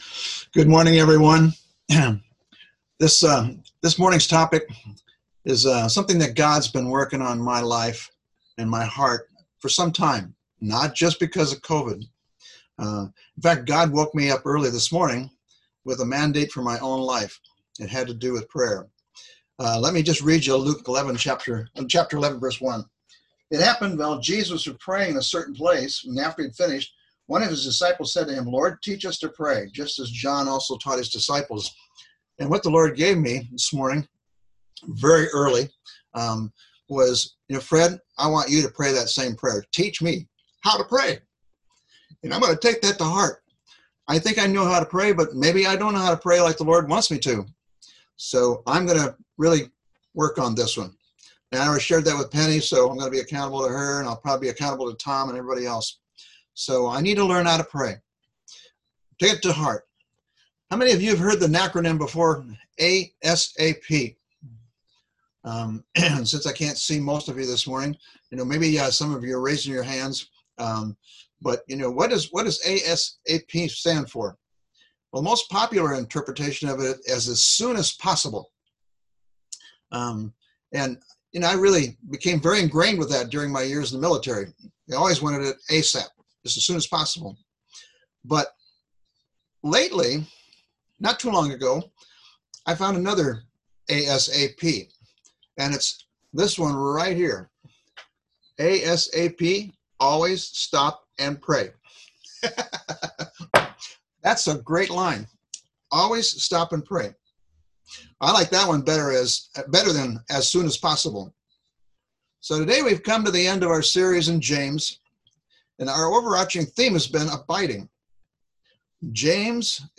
May 17, 2020 Abiding in Prayer (05.17.2020) MP3 PDF SUBSCRIBE on iTunes(Podcast) Notes Discussion Sermons in this Series The ultimate proof of an abiding relationship with Christ is an abounding prayer life.